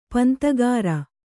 ♪ pantagāra